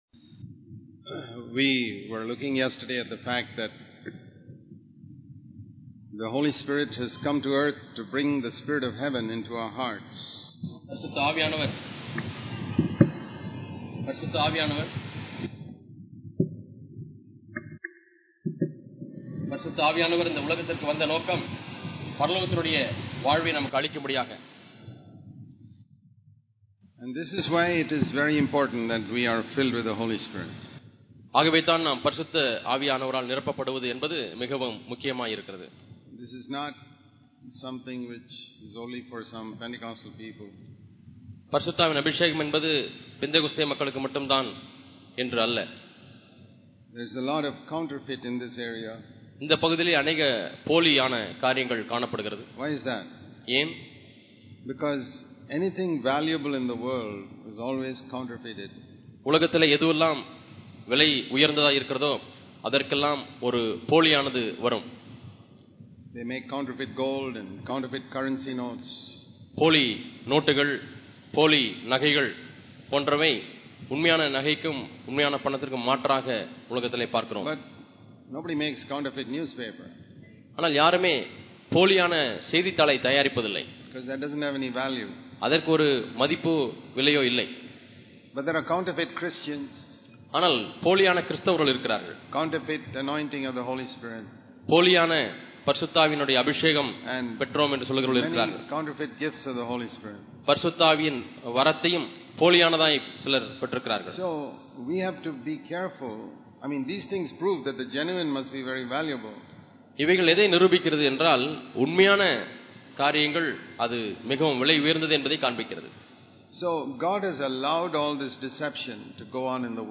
Heavenly Life on Earth Click here to View All Sermons இத்தொடரின் செய்திகள் நம்மை கிறிஸ்துவுக்குள்ளாக தேவன் வைத்தது எப்போது?